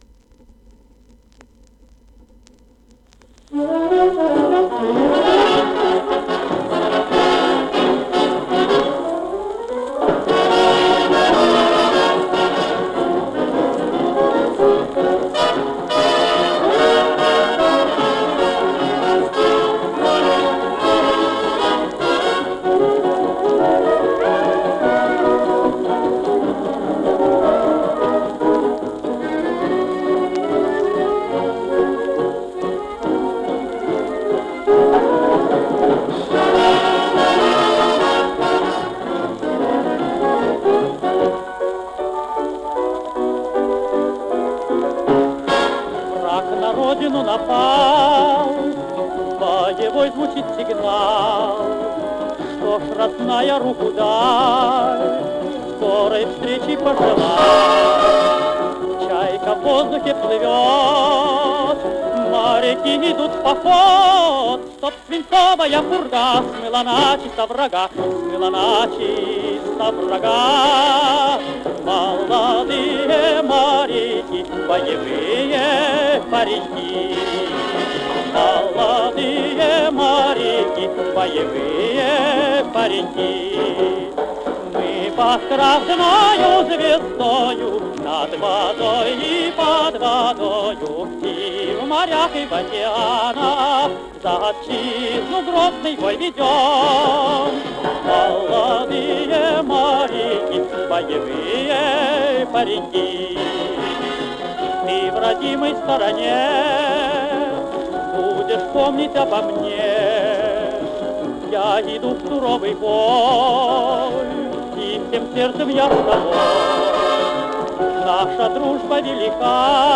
Озвучено с пластинки.